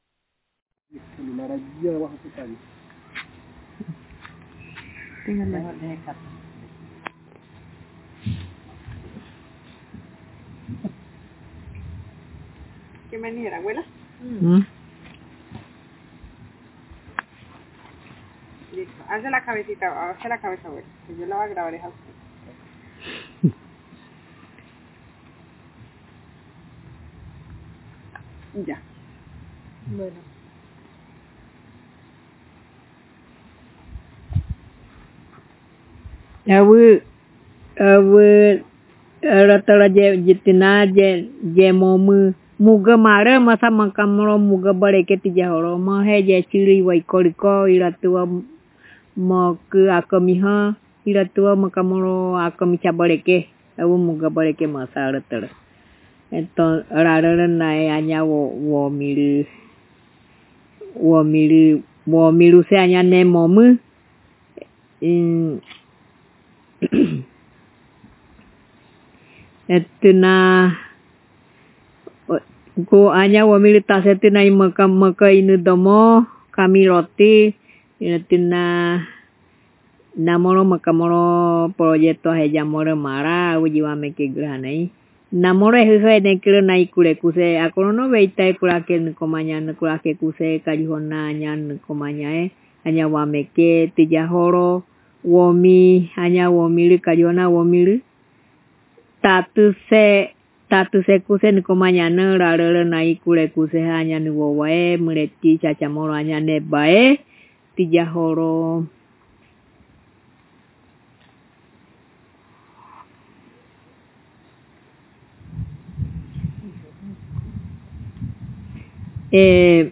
Each member of the family introduces themselves and shares their opinion on a project to recover, document and produce linguistic materials for the language.